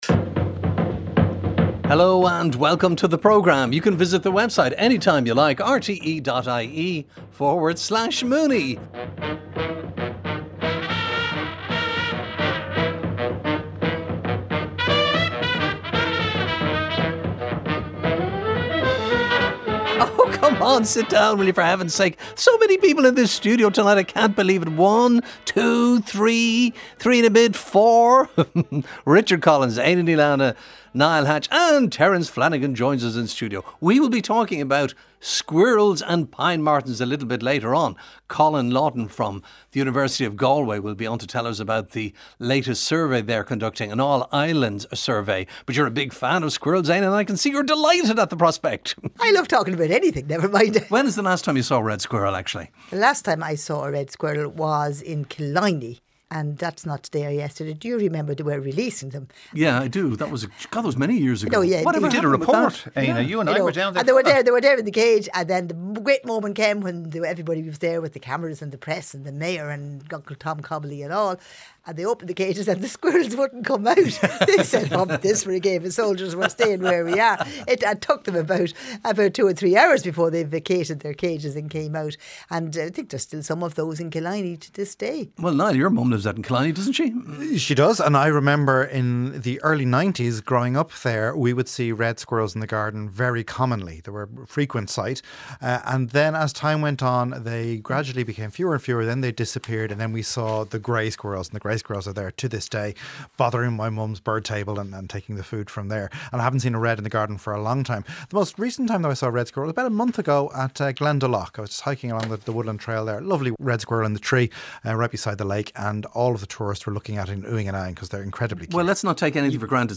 Grey Squirrels, introduced to Ireland in the early 1900s, are now widespread and have impacted the population of native Red Squirrels. They are long believed to descend from a dozen released in Longford in 1911. Our panel discusses whether later deliberate or accidental introductions also added to their numbers and genetics...